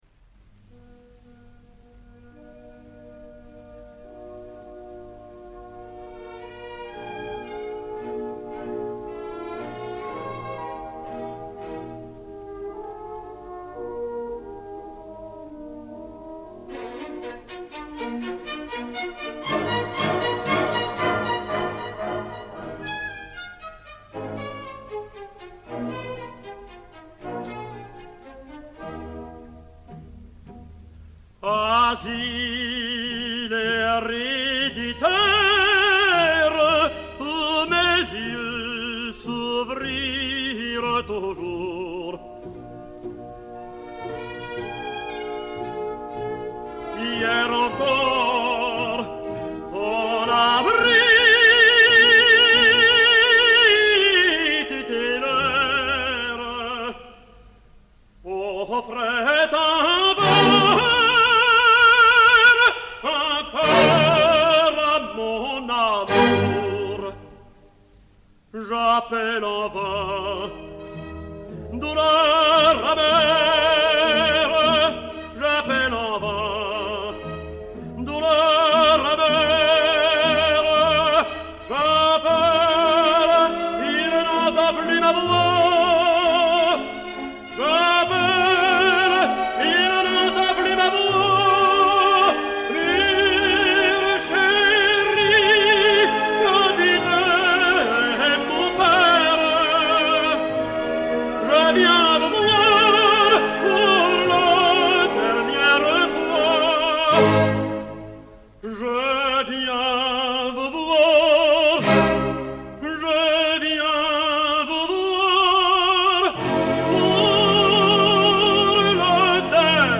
ténor français